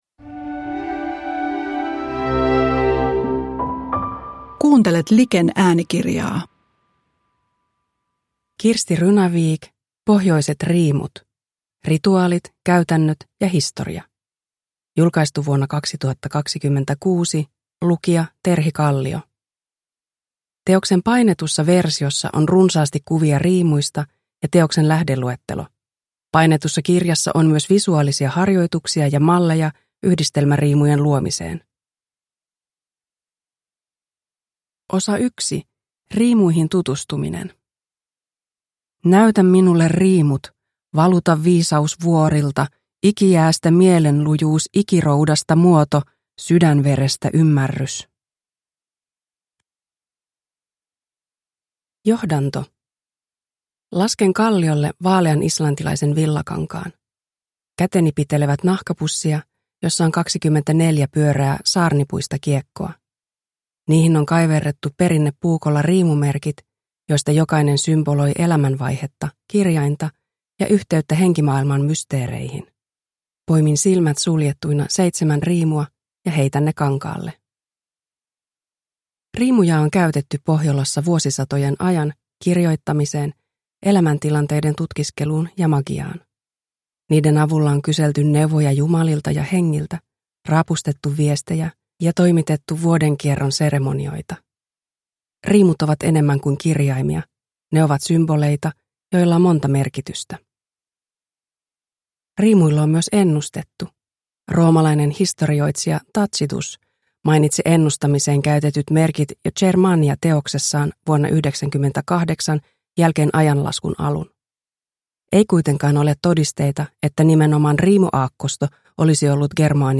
Pohjoiset riimut – Ljudbok